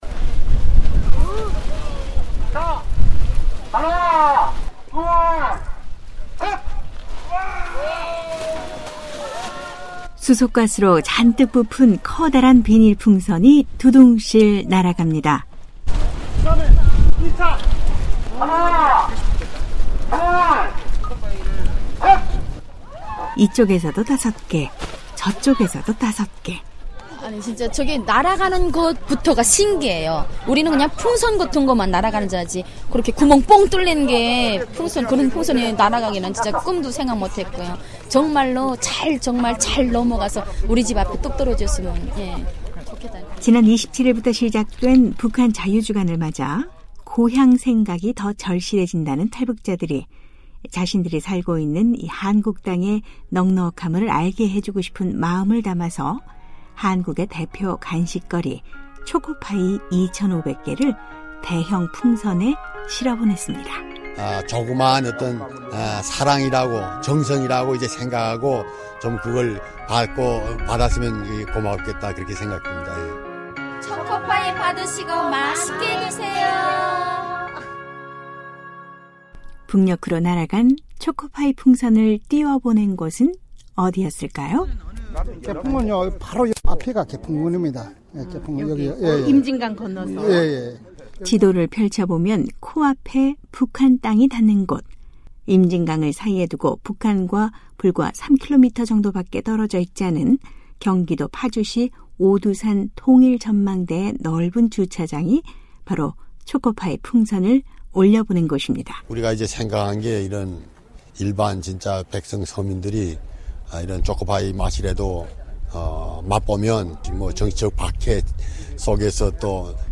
오늘은 북한자유주간 행사로 열린 초코파이 풍선 날려보내기 현장으로 안내하겠습니다.